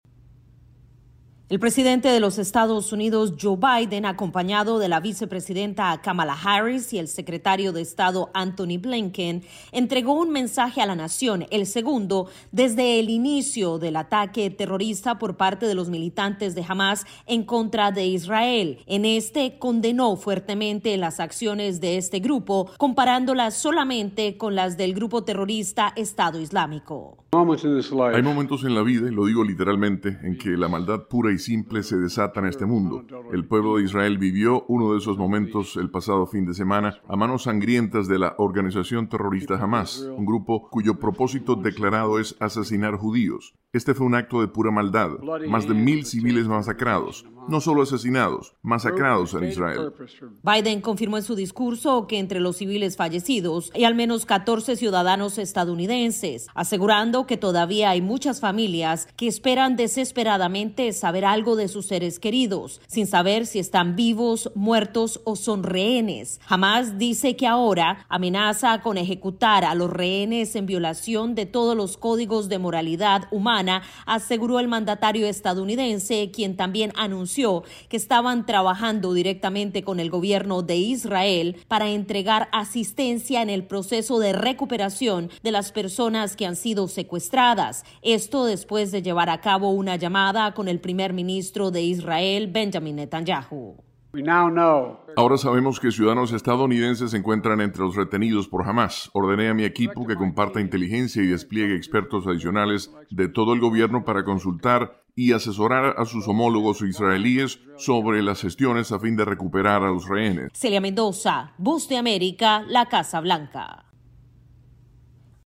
tiene los detalles desde la Casa Blanca…